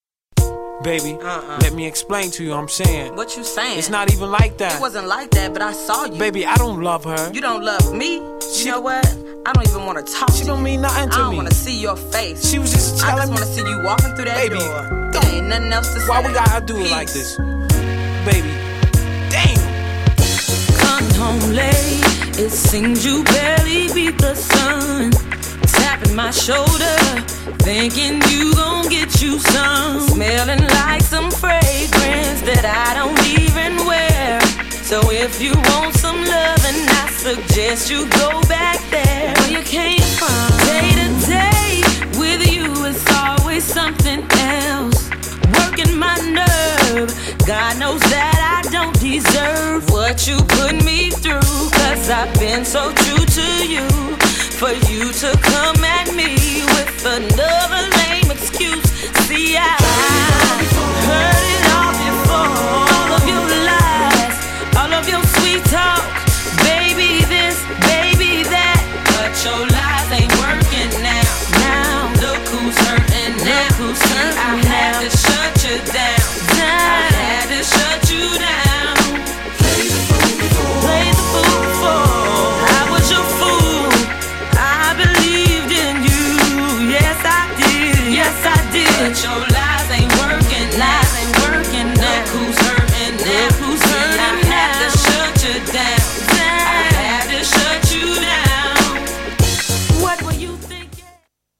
グルービーなサウンドが多く収録された往年のソウルファンにもネオソウルファンにも人気の彼女のデビューアルバム!! 2枚組。
GENRE R&B
BPM 91〜95BPM